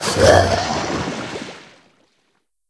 c_seasnake_hit1.wav